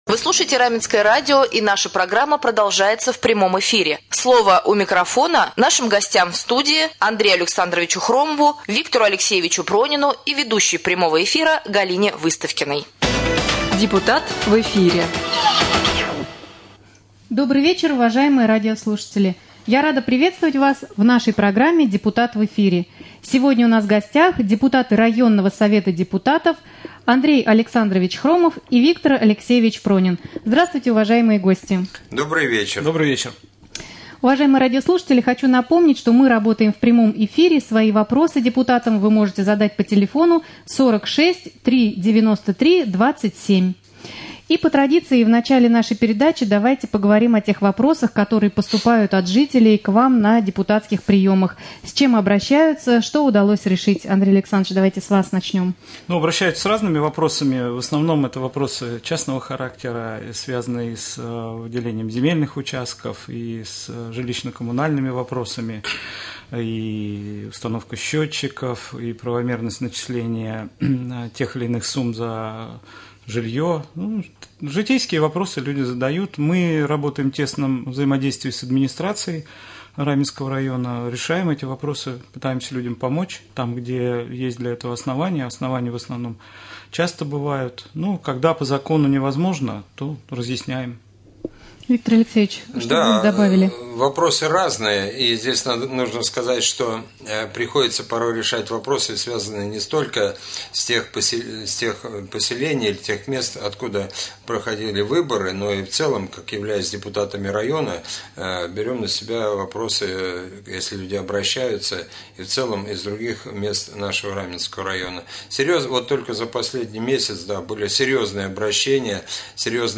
Прямой эфир с депутатами районного Совета депутатов Андреем Александровичем Хромовым и Виктором Алексеевичем Прониным.